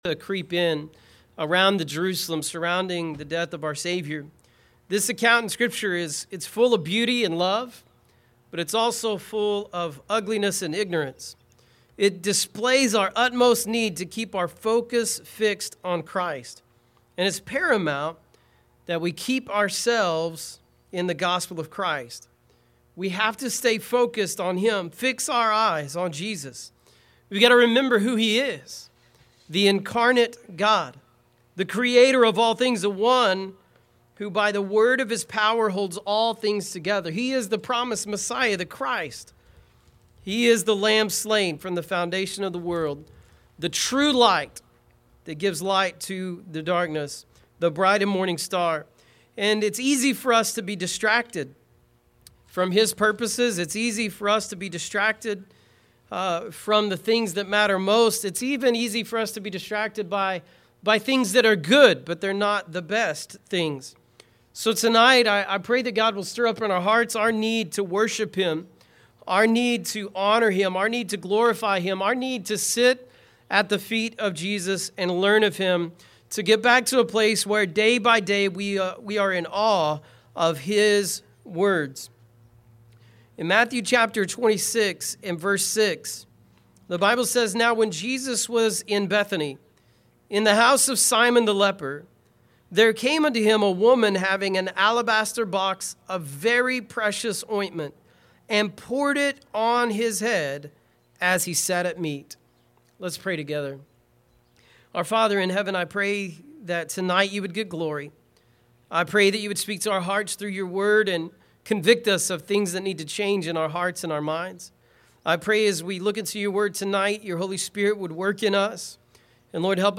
Keep Ourselves in the Gospel of Christ – Welcome to Calvary Missionary Baptist Church!